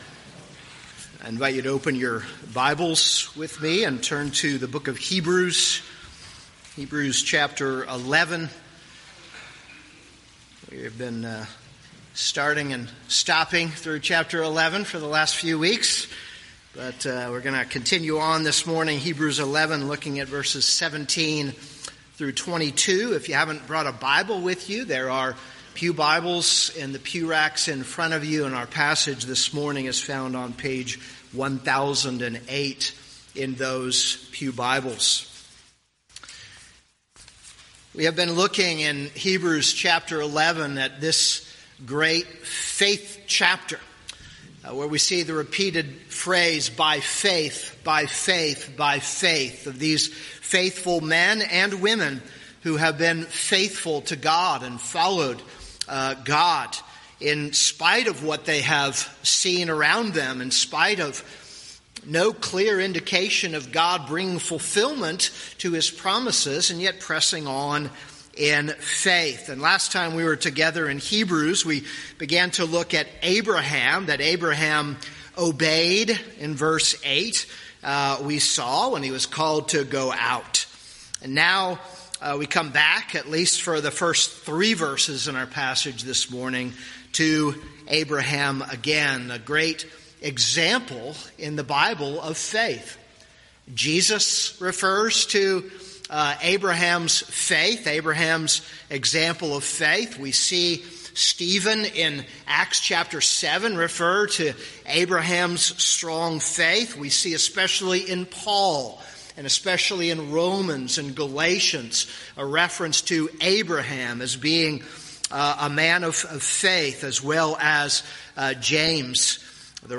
This is a sermon on Hebrews 11:17-22.